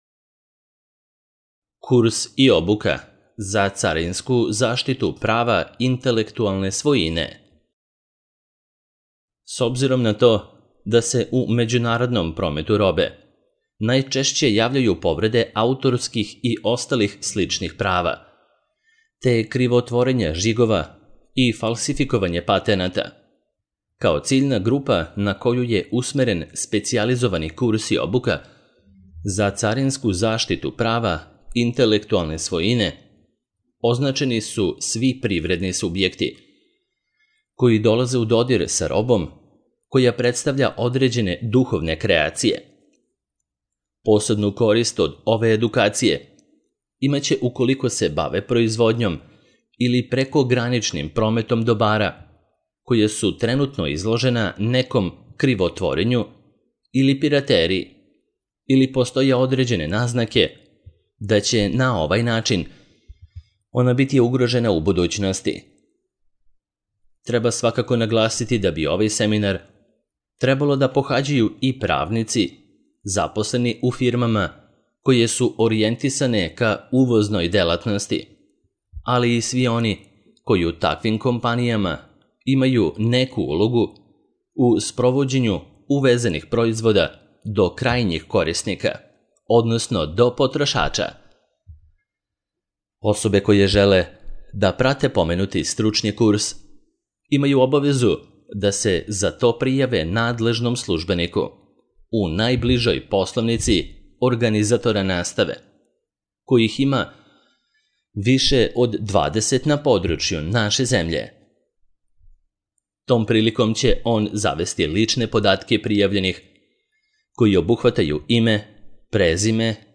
Audio verzija teksta